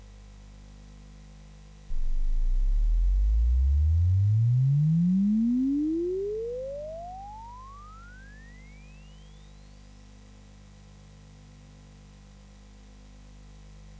sid-measurements - SID chip measurements